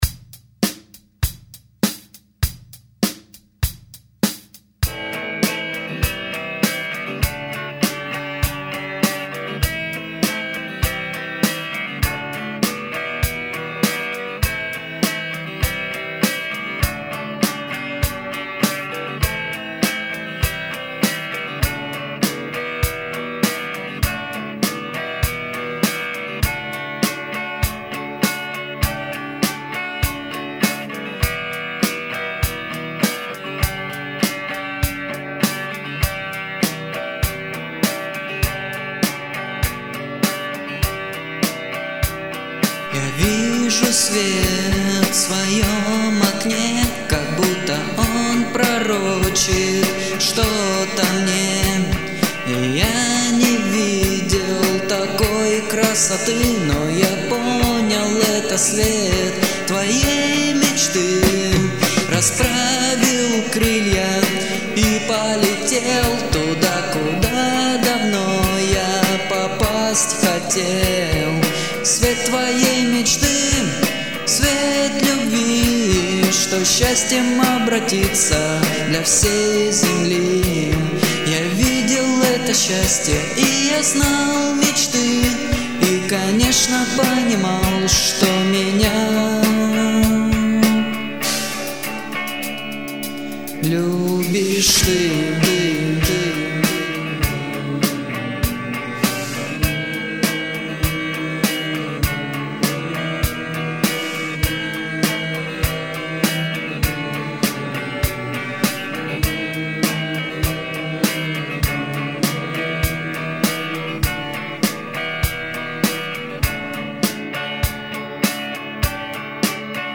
Слова, музыка, вокал - все мое родное :)
Писал все дома на комп. Играл на гитаре и синтезаторе. Пел в гарнитуру.